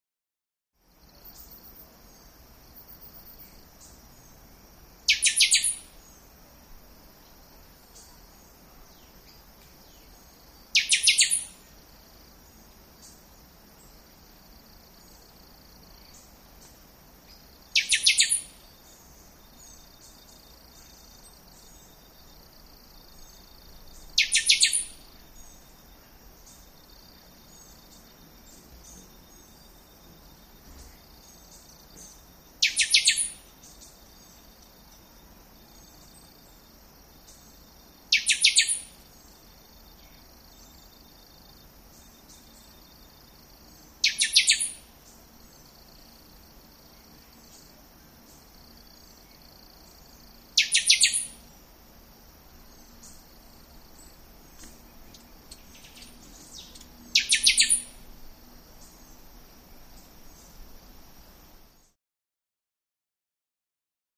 Jungle Atmosphere; Amazon Rain Forest Atmosphere. Light Bird Calls With Single Bird In Foreground.